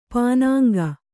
♪ pānāŋga